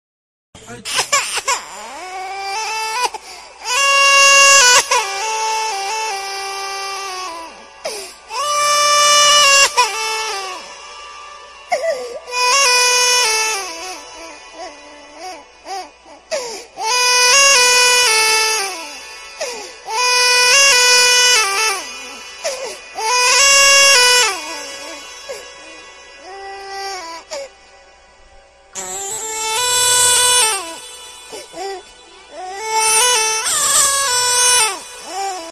autotune baby crying